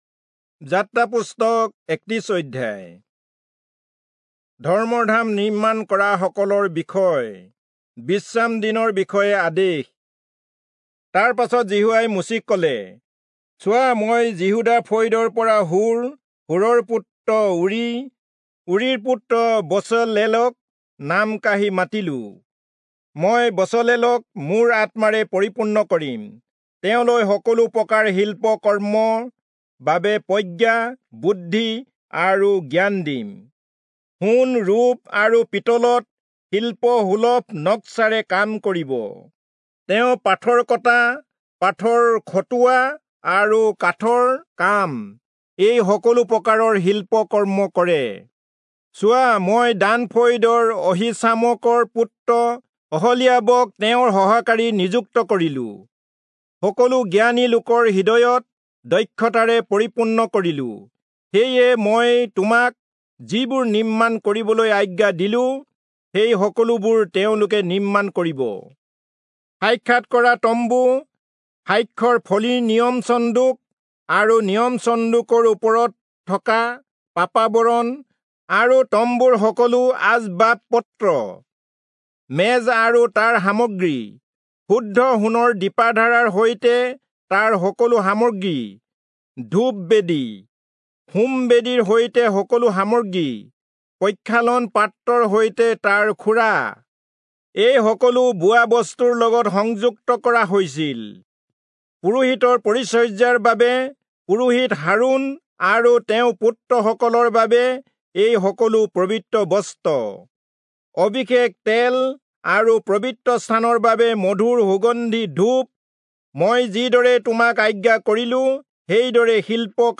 Assamese Audio Bible - Exodus 30 in Ervpa bible version